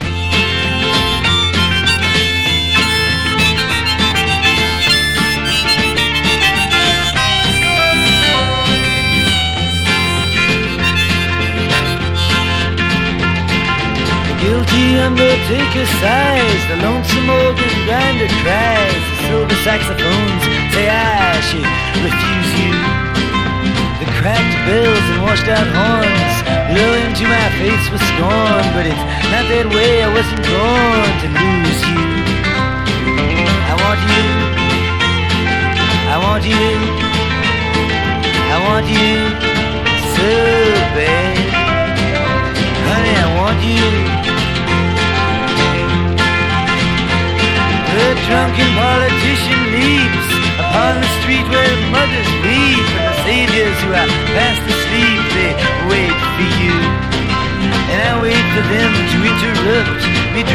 ROCK / S.S.W./A.O.R. / FOLK / FOLK ROCK / 60'S ROCK
アメリカン・フォーク/フォーク・ロックの古典をコンパイル！